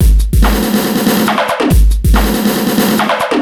E Kit 14.wav